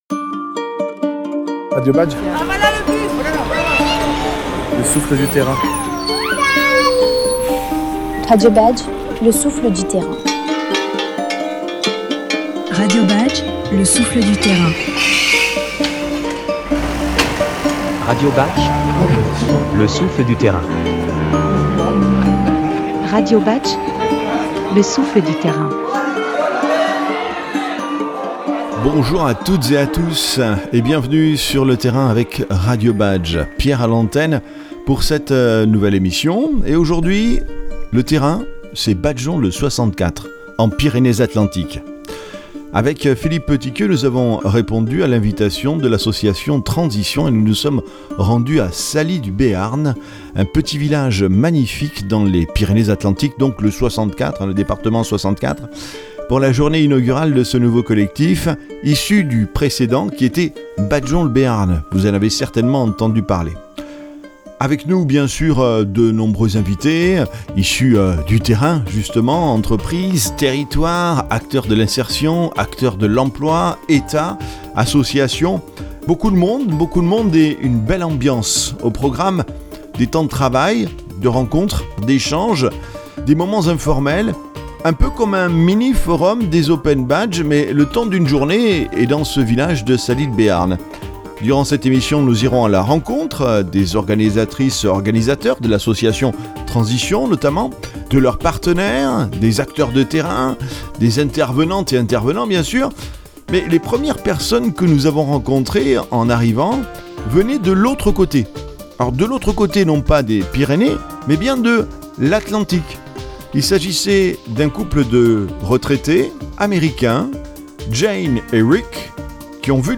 Le 3 avril 2025 avait lieu à Salies de Béarn le lancement du collectif Badgeons le 64.